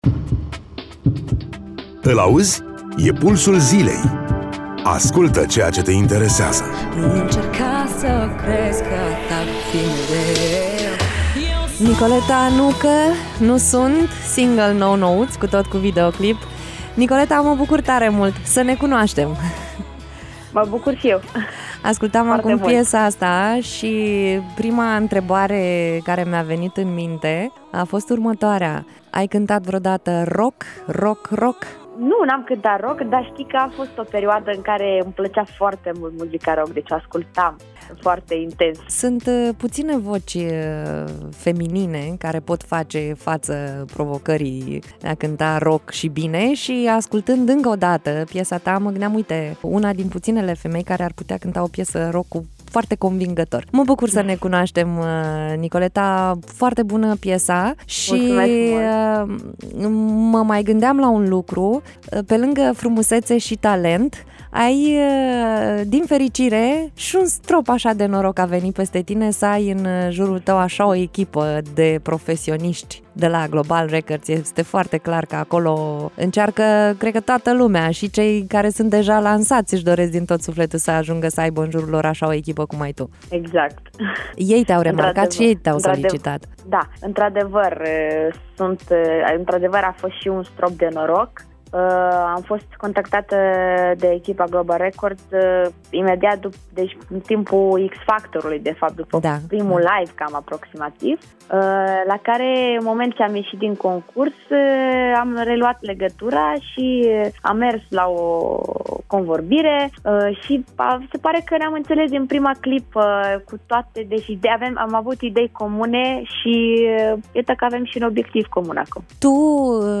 în direct la Radio Iaşi
interviu